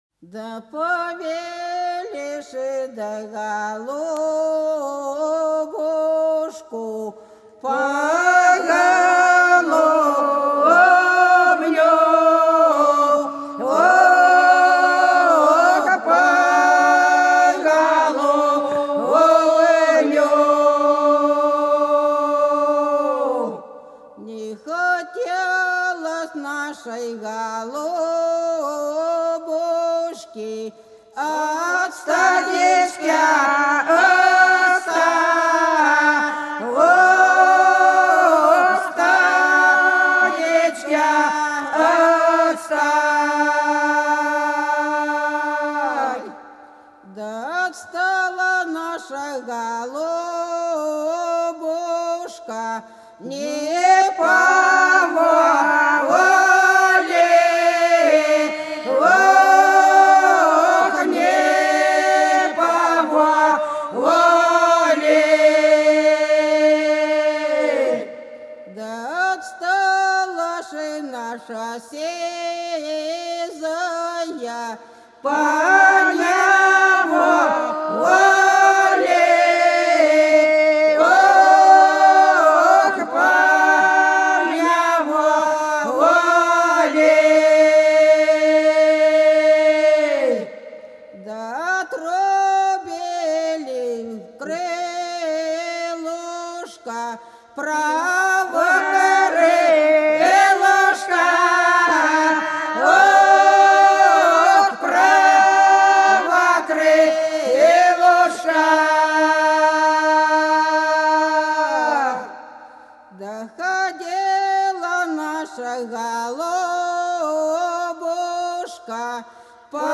Хороша наша деревня Повили да голубушку - свадебная (с. Иловка)
06_Повили_да_голубушку_-_свадебная.mp3